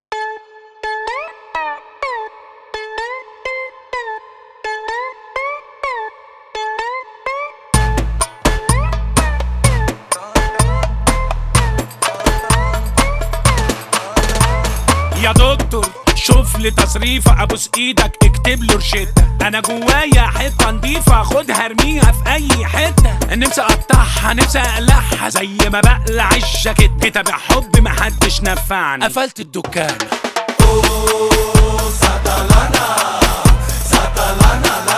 Egyptian Pop
Жанр: Поп музыка